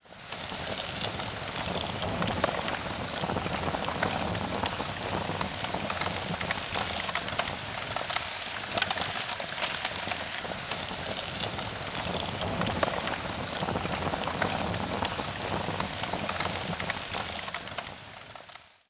fire-combustion-sound